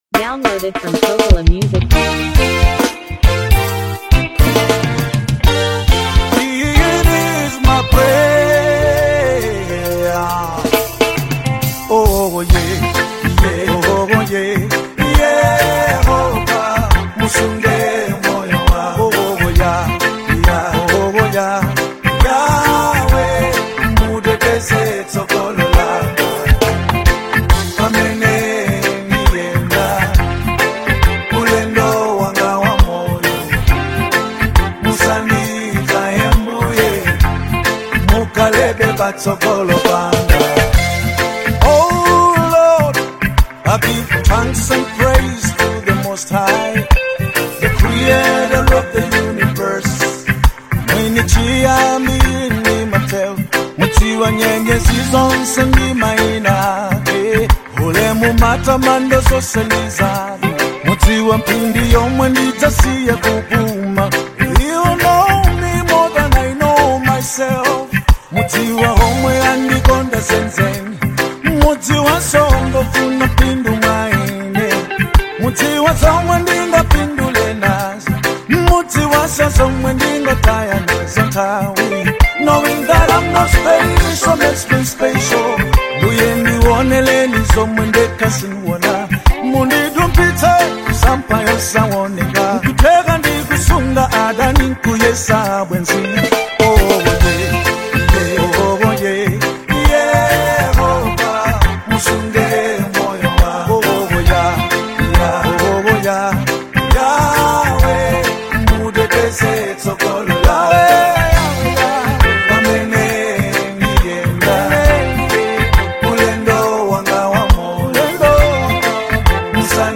powerful love ballad